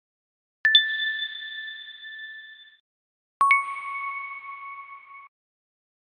diamond-sound-effect-high-quality-free-download_6Ry3B1I.mp3